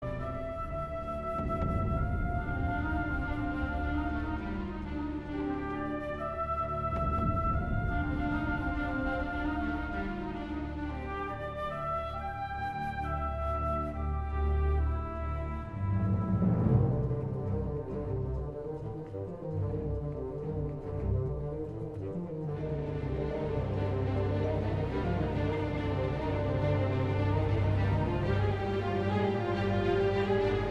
a classic suspense score with little tonality.